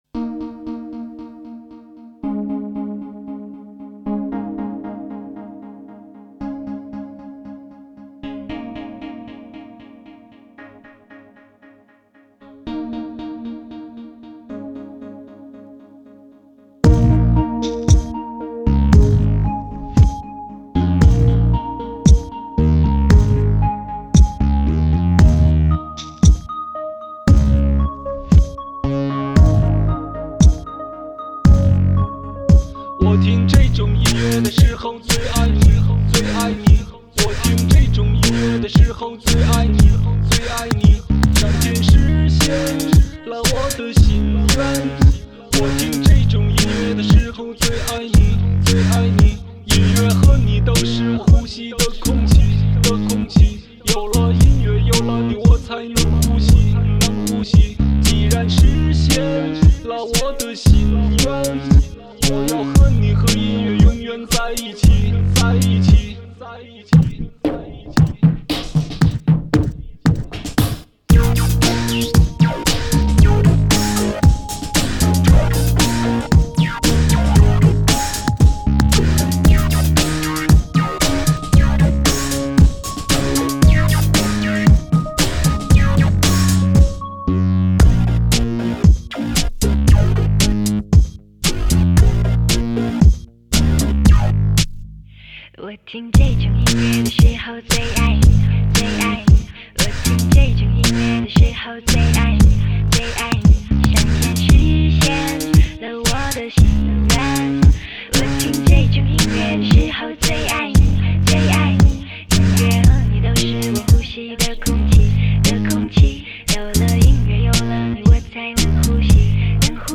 【类别】 内地流行